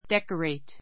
decorate 中 dékəreit デ コレイ ト 動詞 飾 かざ る, 装飾 そうしょく する 関連語 「飾ること」は decoration . decorate a Christmas tree decorate a Christmas tree クリスマスツリーに飾りをつける We decorated our rooms with flowers.